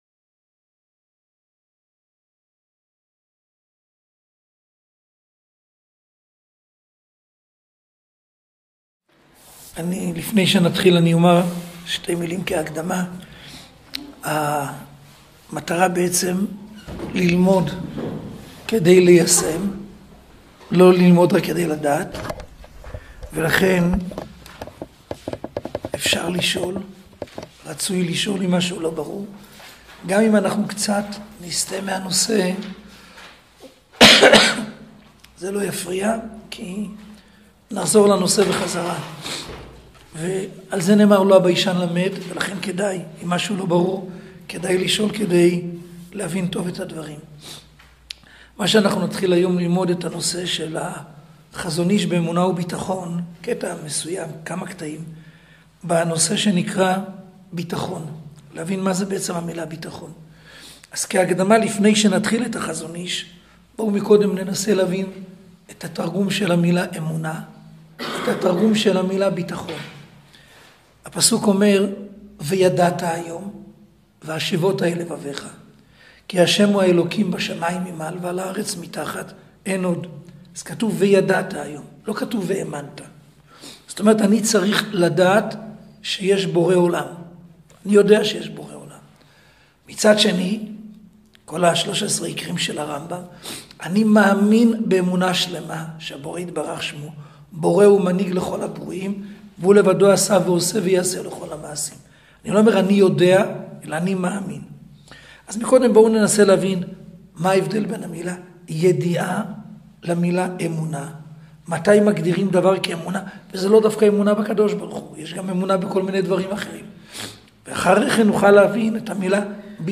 Урок 1. Верим ли мы словам мудрецов?